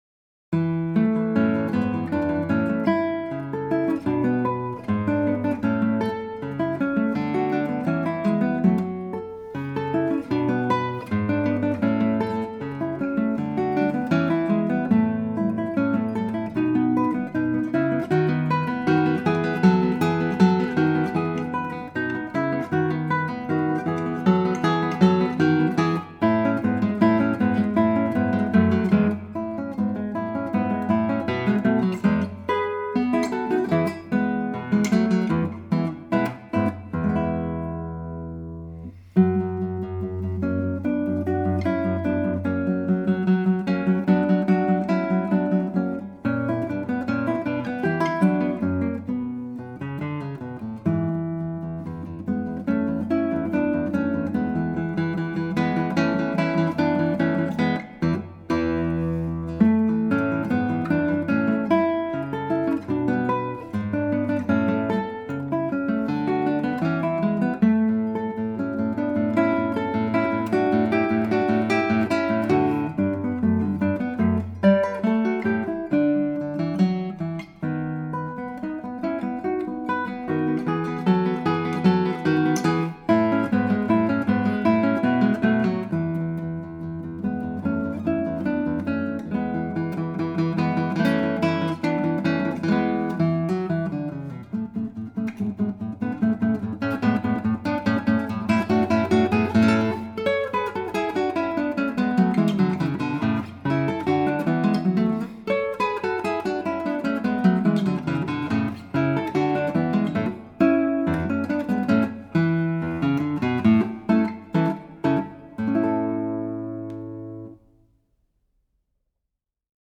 Voicing: Guitar CD